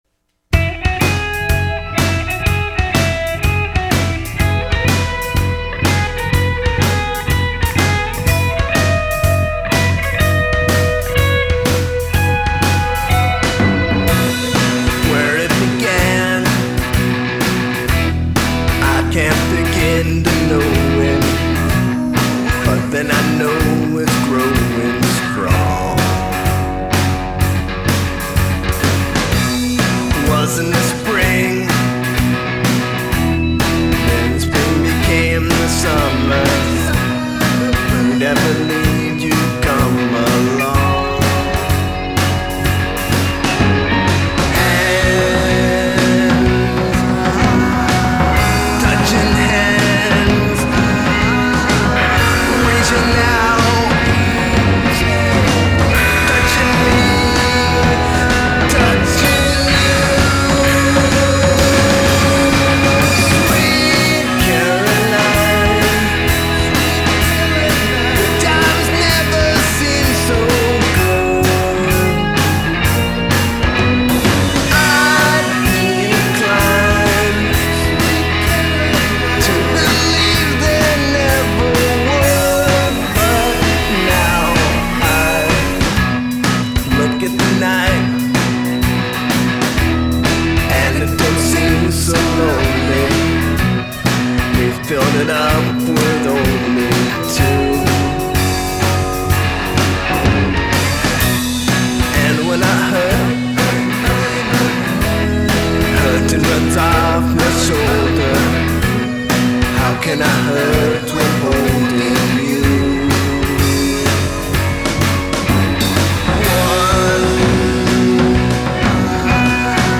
Bass, Lead Vocal
Guitar, Backing Vocal
Drums